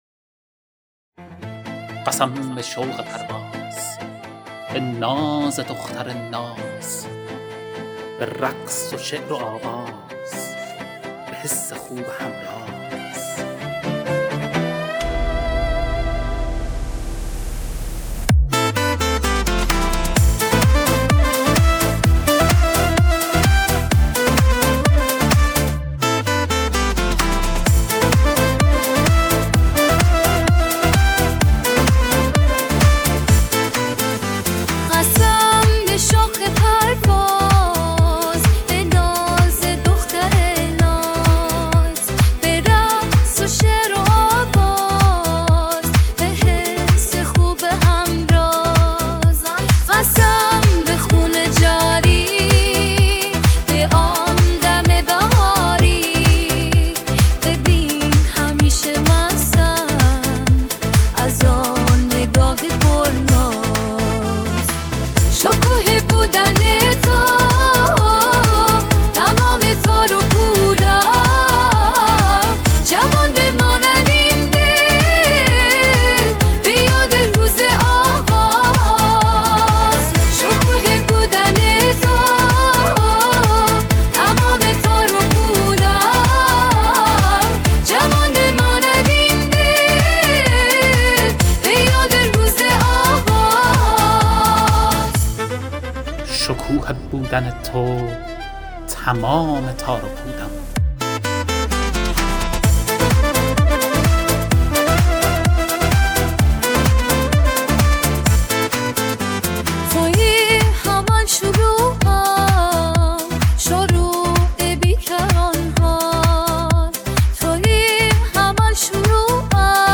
تولید: با کمک هوش مصنوعی